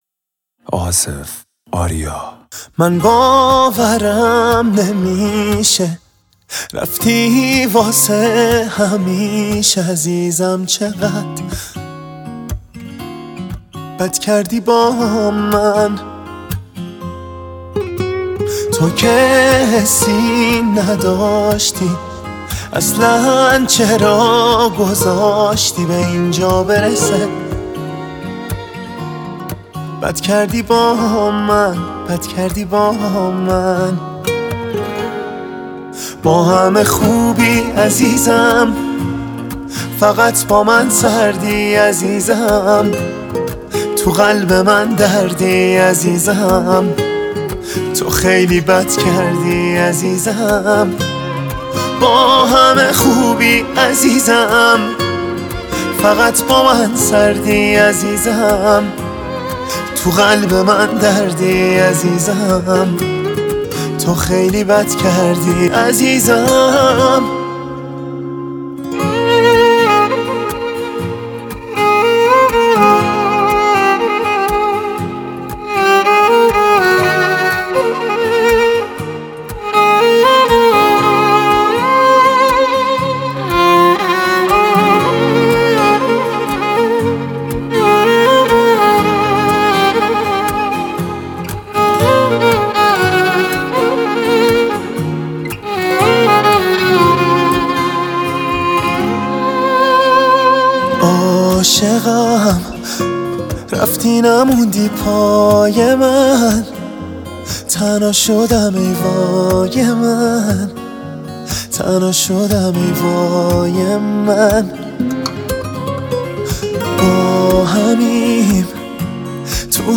Slow Version